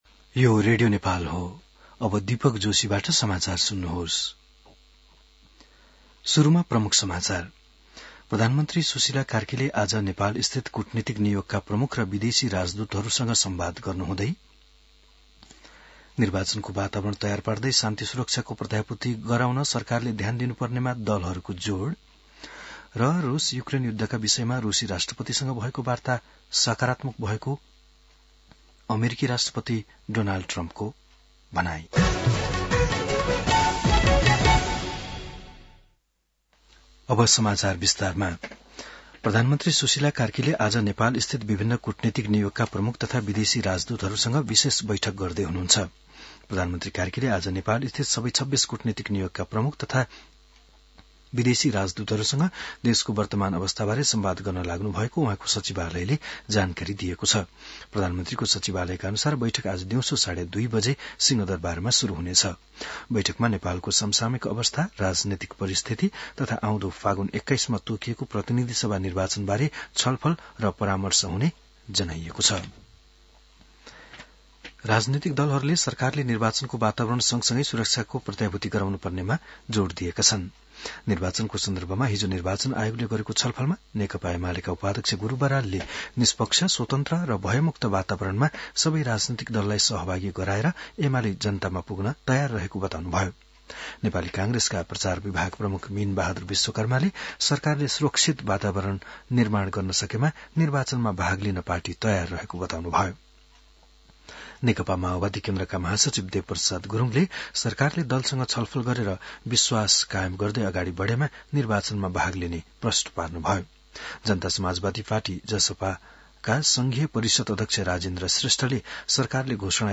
बिहान ९ बजेको नेपाली समाचार : ३१ असोज , २०८२